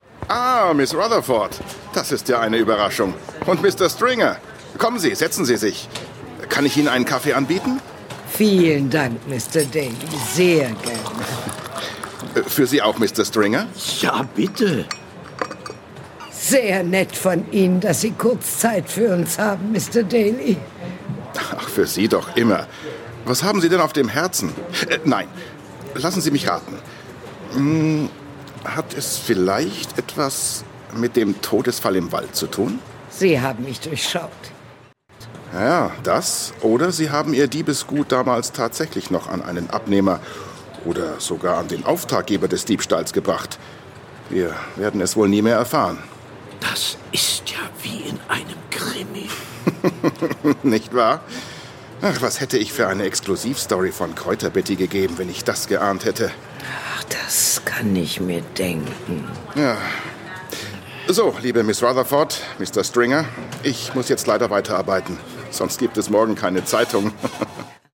Sprachproben
Männlich
Mezzo-Sopran / Bariton / Mittel
Alt / Bass / Tief
Aber auch Sachtexte z.B. für Imagefilme, E-Learning, Werbung oder Dokumentationen, erwecke ich durch meine warme, sonore, vertrauenerweckende Stimme zum Leben.